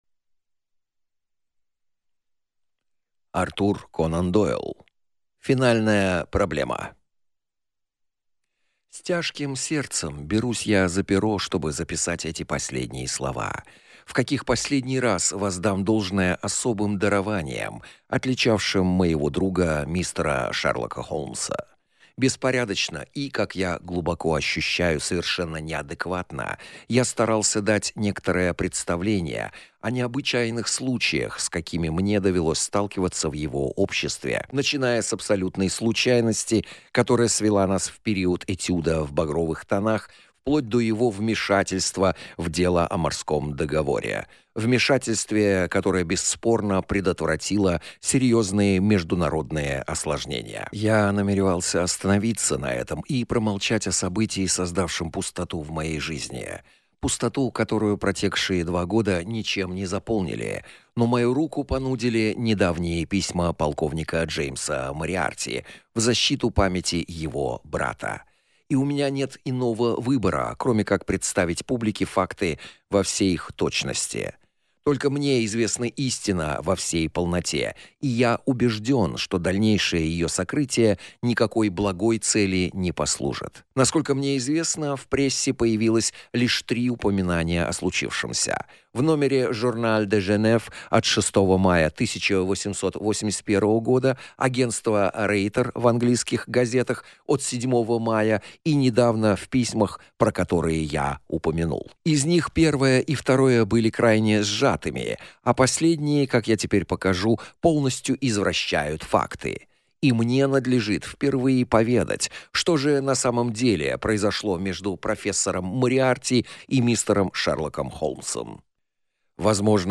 Аудиокнига Финальная проблема | Библиотека аудиокниг